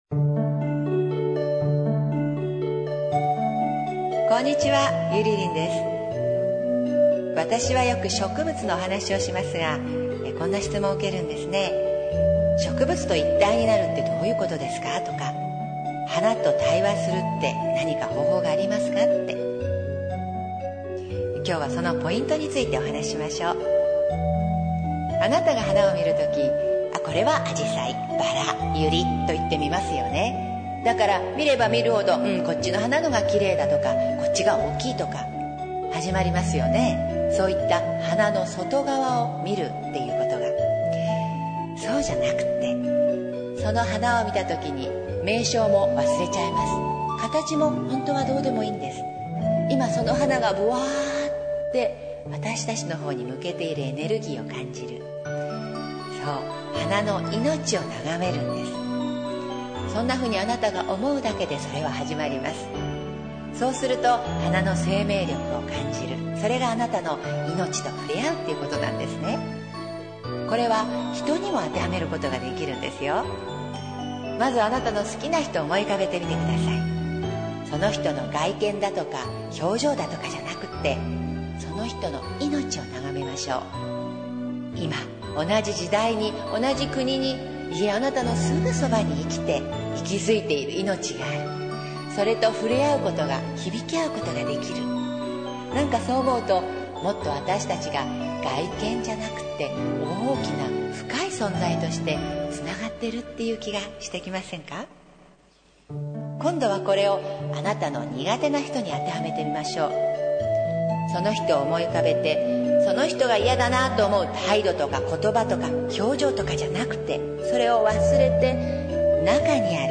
３分間フリートークです。